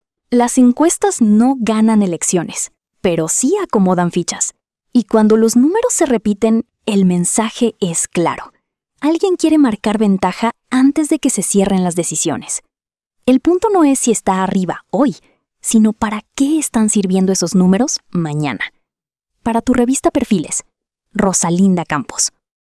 🎙 COMENTARIO EDITORIAL 🎤